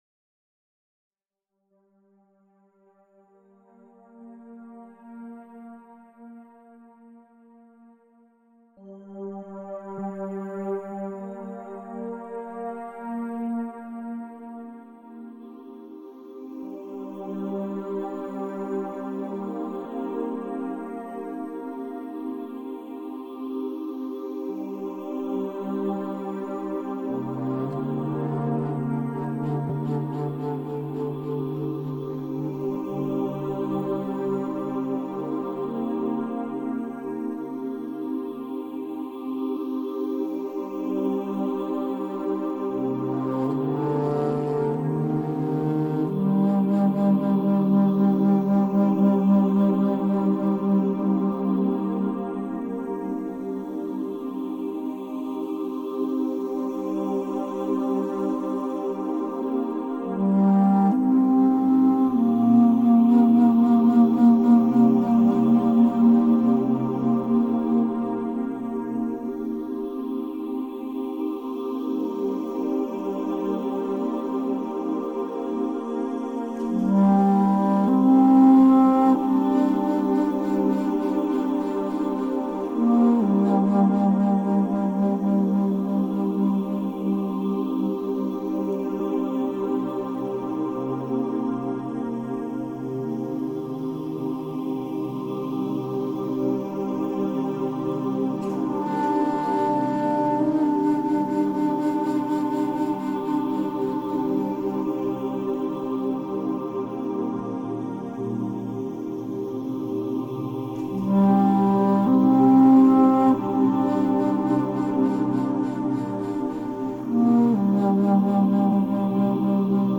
Тета-волны